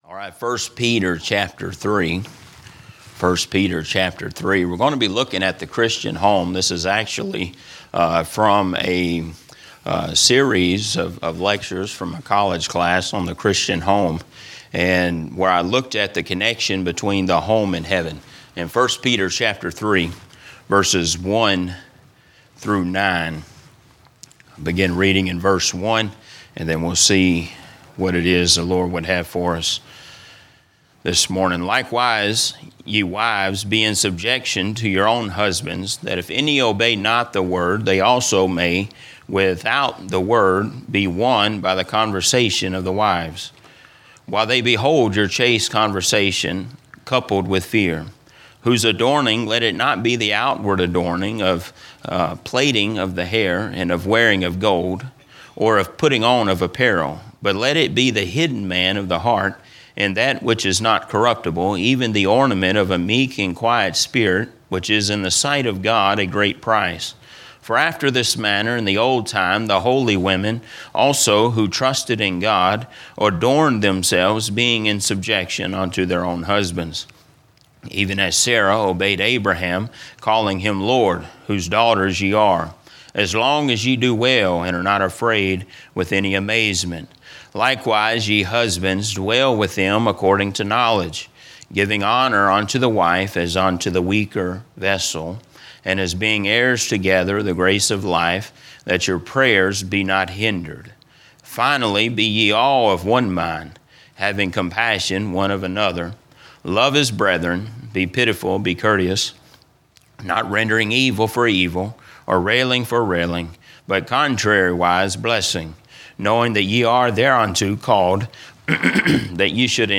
A message from the series "General Teaching."